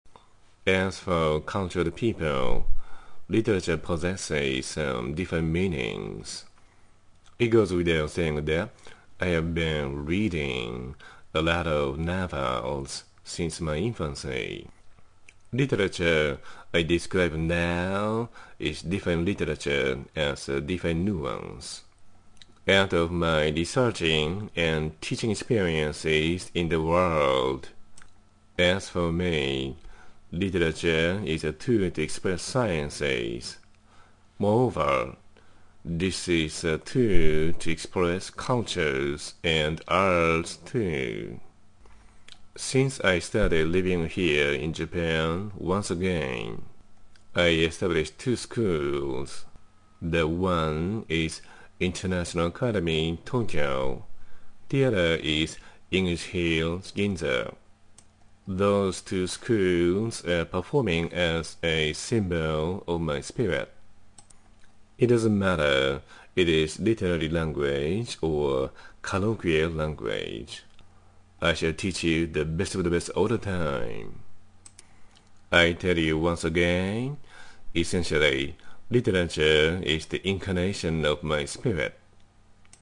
英語音声講義